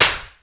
Claps
SLAP   1.WAV